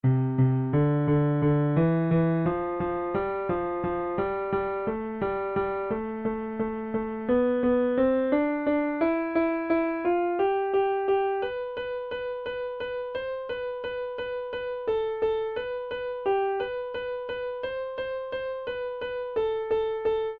Energia usada por pessoa em Portugal de 1965 até 2023 Sonificação da energia usada para perceber as diferenças de consumo ao longo dos anos Dados ...